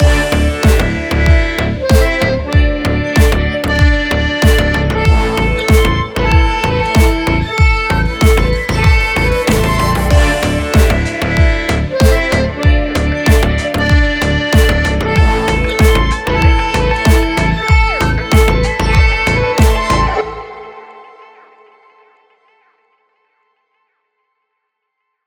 אתגר אקורדיון.wav
אז תוך צפייה בתוכנית אירוח אמריקנית, נתקלתי בקטע הזה: מישהי רנדומלית מהקהל עולה ומנגנת באקורדיון
מה שאהבתי פה הוא דווקא האקראיות - ניכר שהיא לא נגנית מקצוענית, לא יושבת בדיוק על הקצב, לא התאמנה, וגם יש רעש של קהל ברקע. חתכתי מזה 4 תיבות, סה"כ 2 אקורדים, אחלה אתגר…
אתגר אקורדיון.wav מהירות הקטע : כ-107BPM סולם : Dm - Am